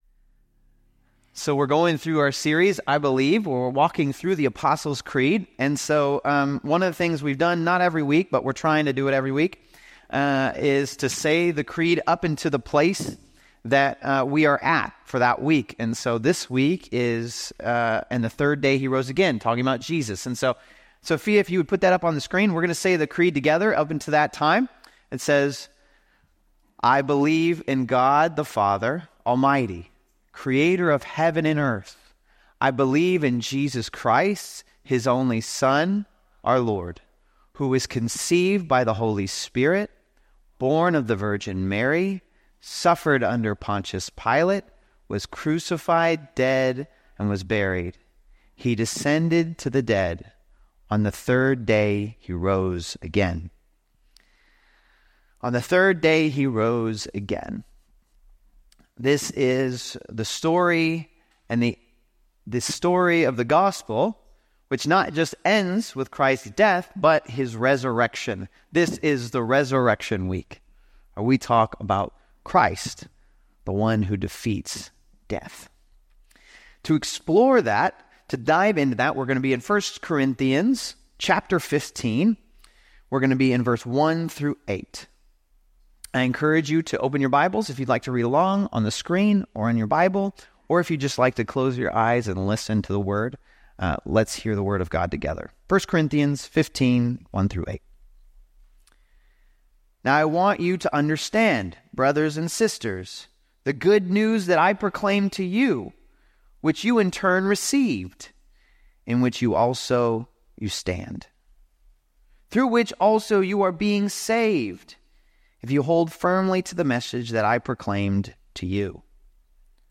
2024 On The Third Day He Rose from the Dead Preacher